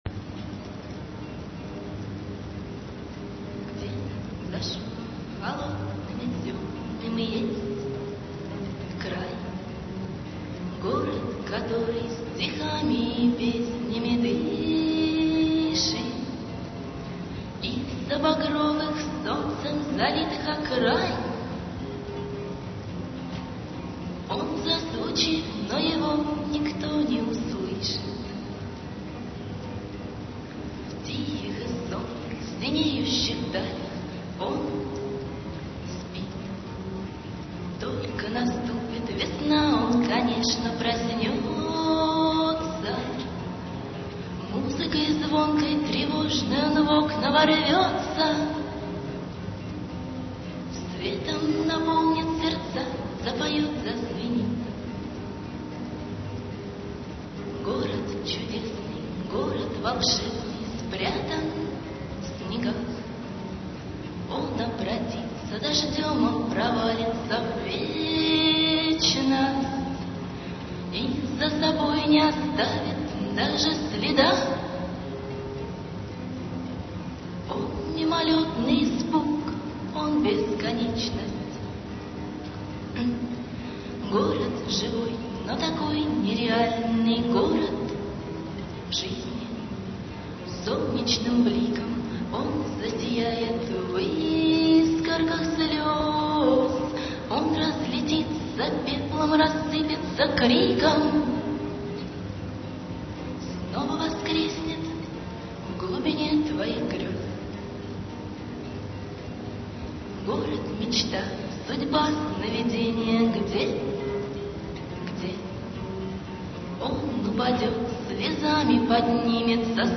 Бардовский концерт
декабрь 2000, Гимназия №45
488 kb, авторское исполнение